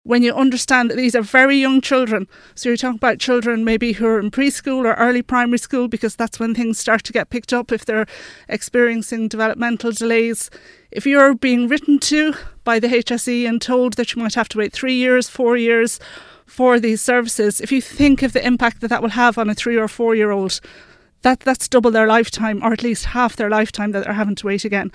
Speaking on Kildare Today